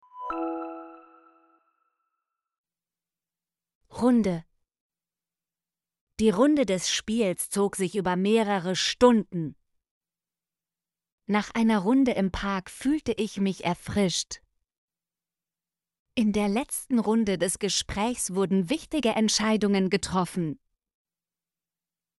runde - Example Sentences & Pronunciation, German Frequency List